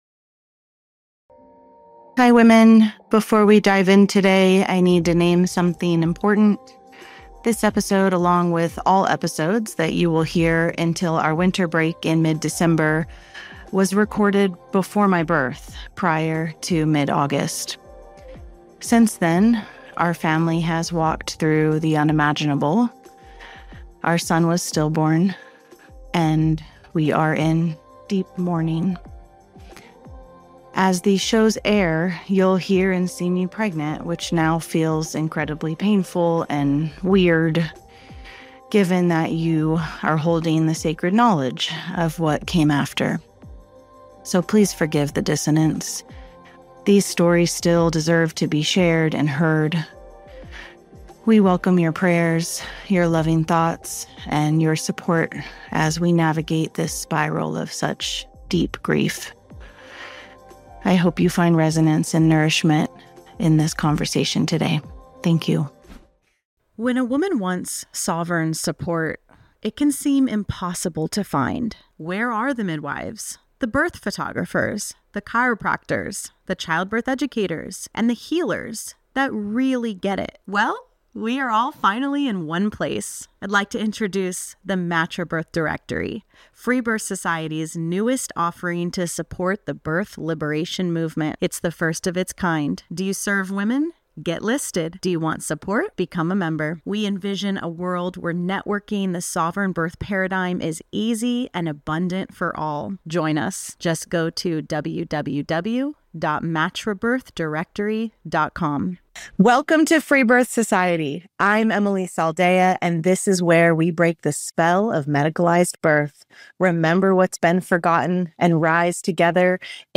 This conversation is equal parts history, testimony, and initiation.